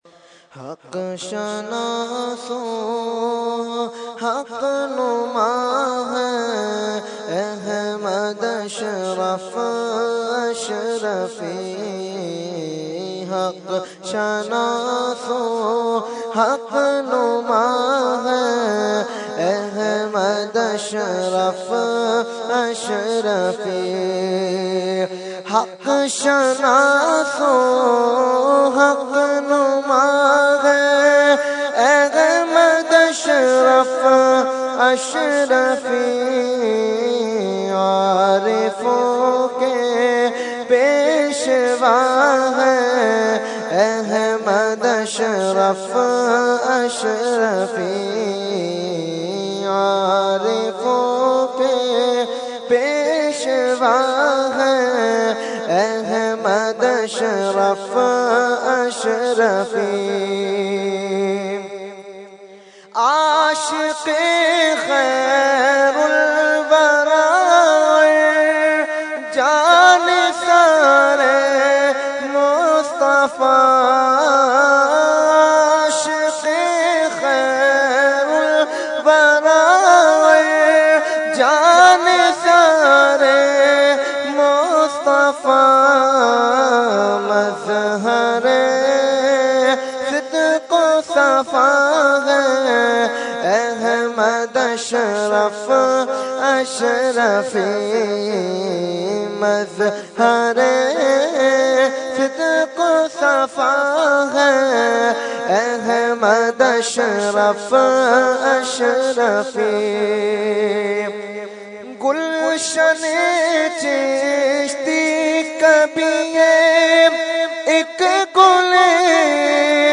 Category : Manqabat | Language : UrduEvent : Urs e Makhdoom e Samnani 2015